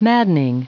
Prononciation du mot maddening en anglais (fichier audio)
Prononciation du mot : maddening